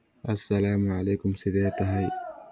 Somali-tts / tts_somali_finetuned like 0 Follow Somali-tts 9